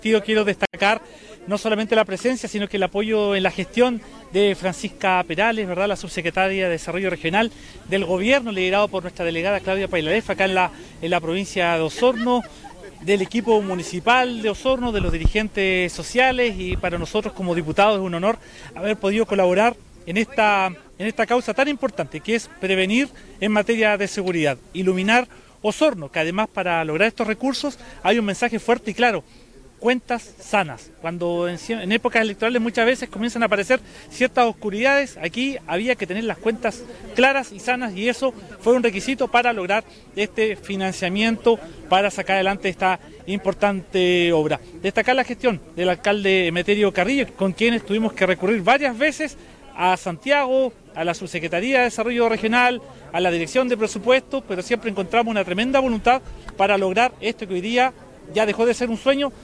Este martes, se dio inicio al recambio de 15.872 luminarias en el radio urbano de Osorno, en un acto celebrado en la Plaza España de Rahue Alto.
En tanto, el Diputado Héctor Barría, destacó el aporte del nivel central para entregar mejoras en la calidad de vida a los habitantes de la comuna de Osorno.